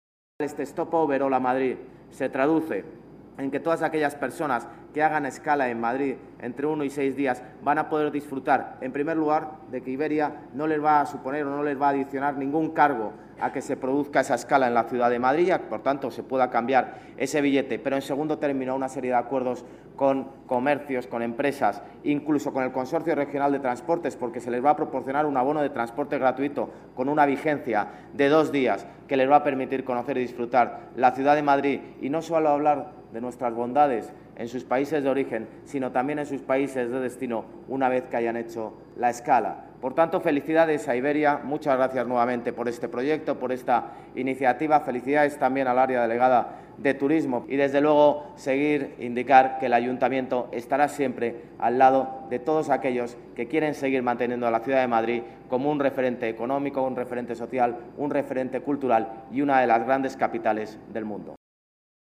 Nueva ventana:Almeida explica en qué consiste este proyecto que va a hacer más agradable la estancia en las escalas en la ciudad de Madrid